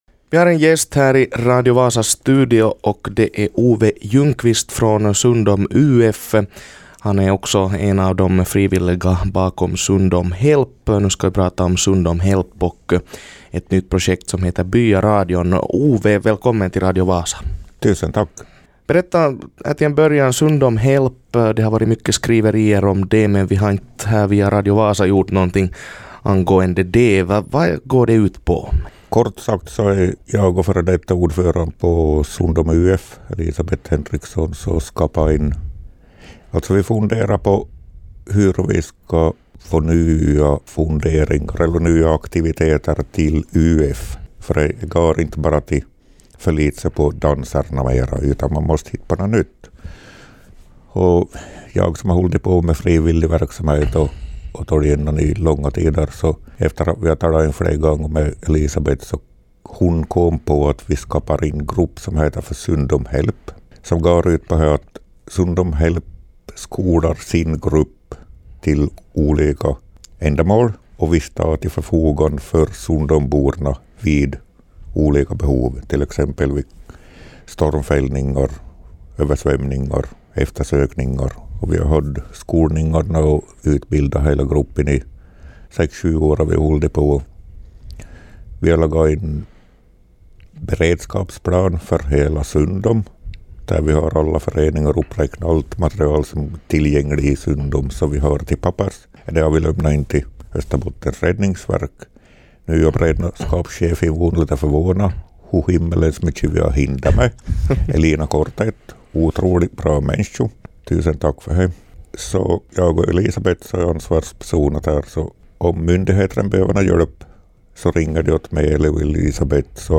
intervjuar.